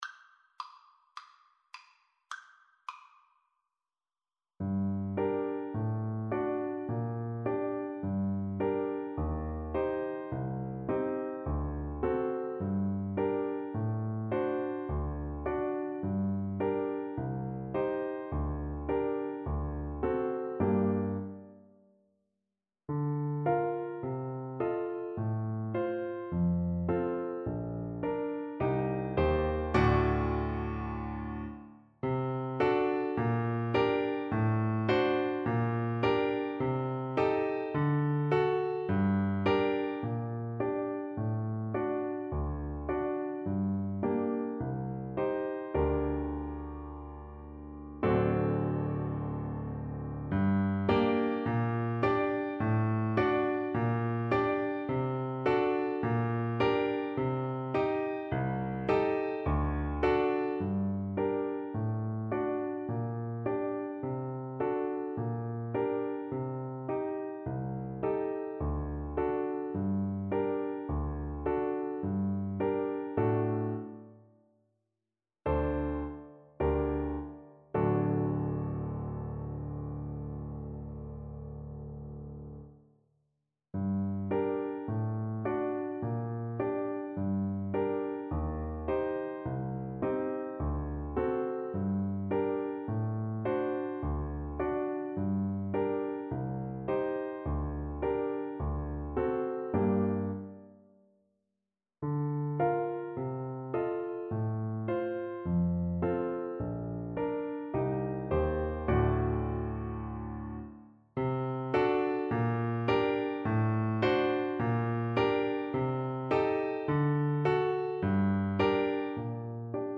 Clarinet
G minor (Sounding Pitch) A minor (Clarinet in Bb) (View more G minor Music for Clarinet )
Allegretto =c.140
4/4 (View more 4/4 Music)
Classical (View more Classical Clarinet Music)